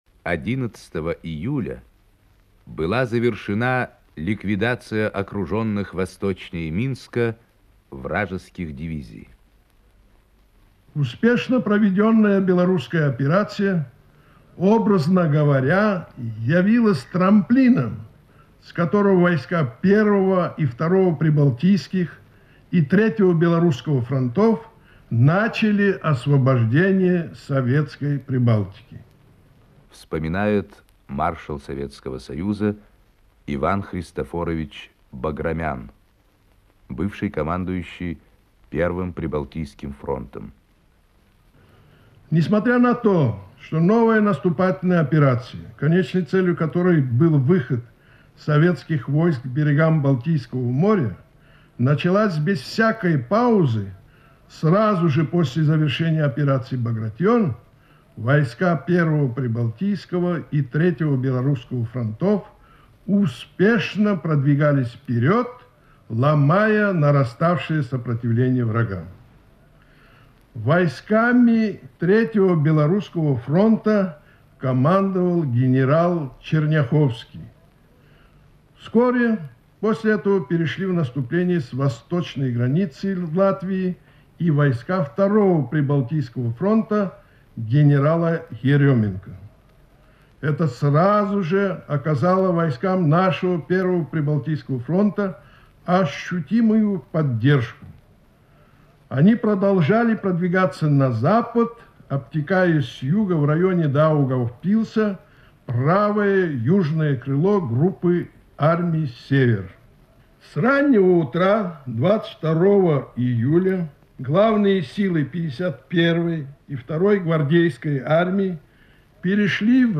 Маршал СССР, дважды Герой Советского Союза Иван Баграмян рассказывает об освобождении Белоруссии от фашистских захватчиков (Архивная запись).